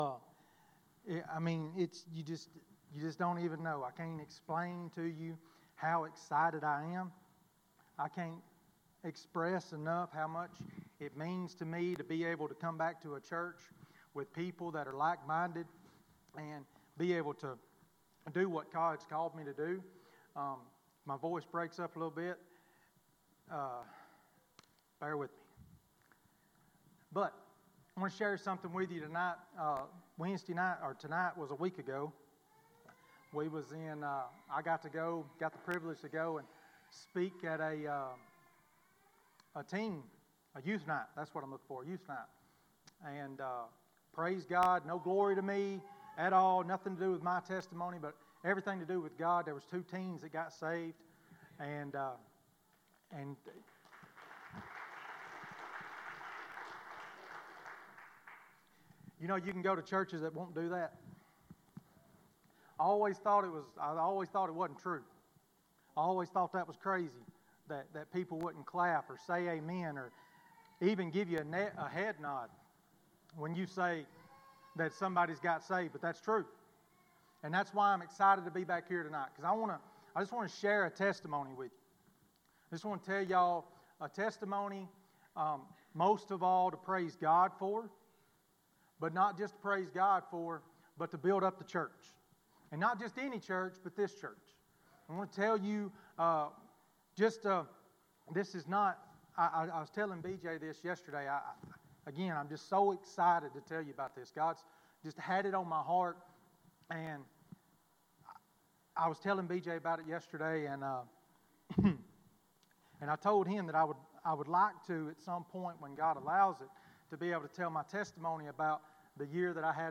Testimony.mp3